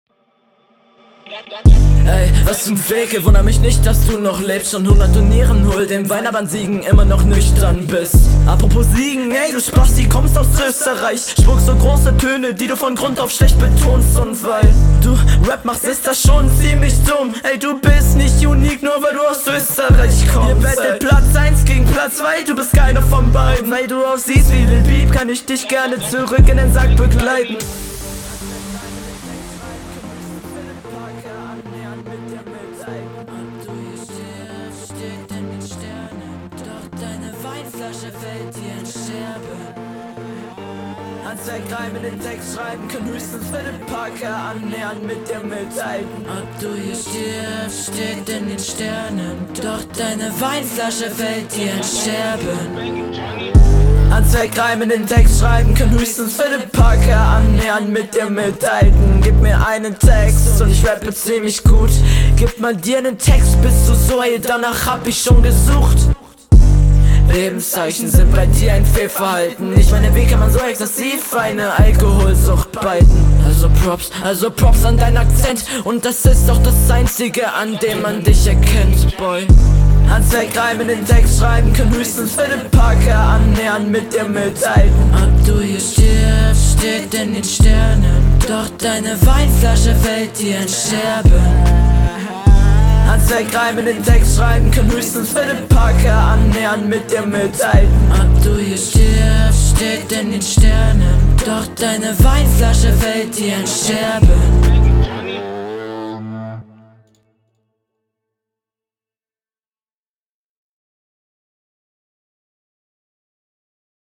Soundtechnisch sehr geile Runde.